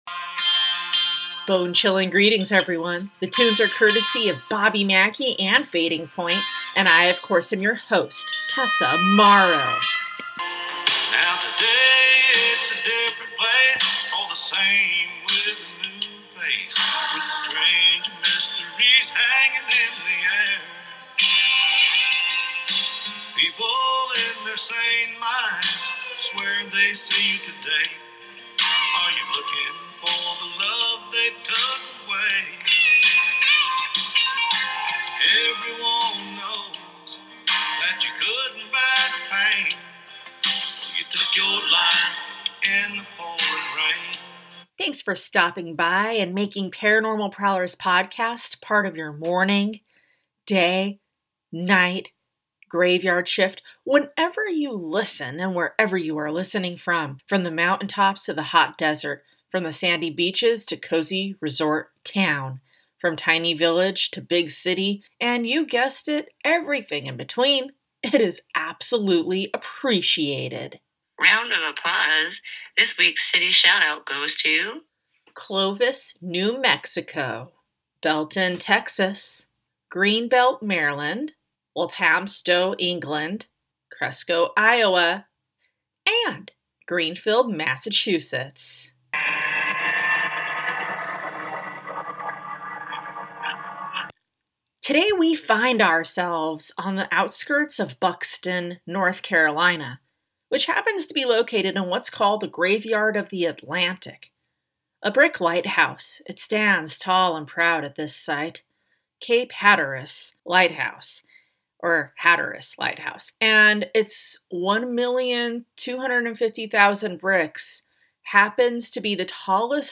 Cat Meowing